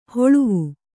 ♪ hoḷuvu